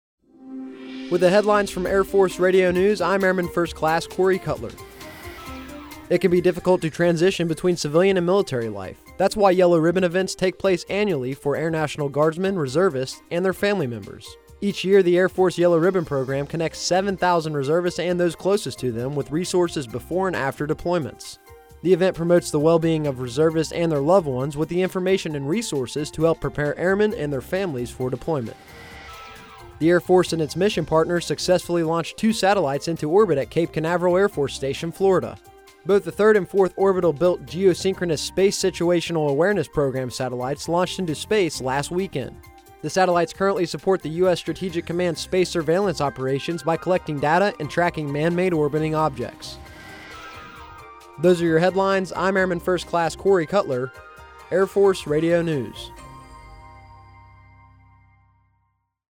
Air Force Radio News B 24 Aug 2016